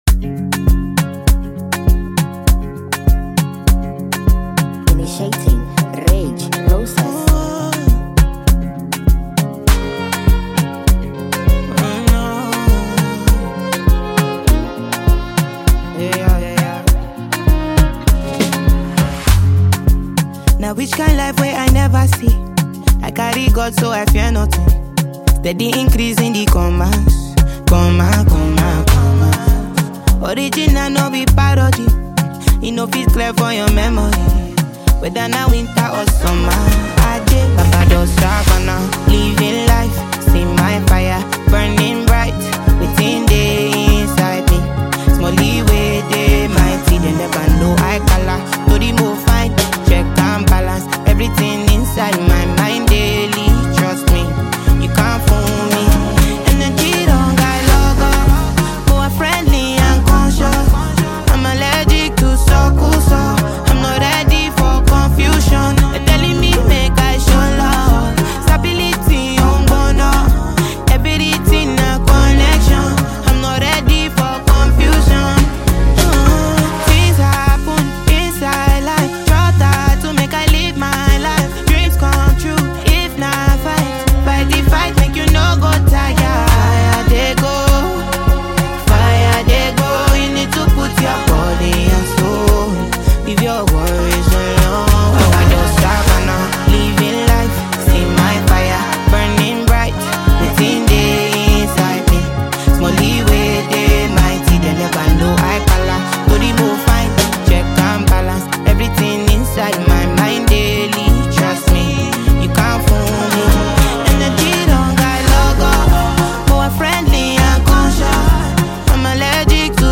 Fabulous Nigerian female singer
This catchy masterpiece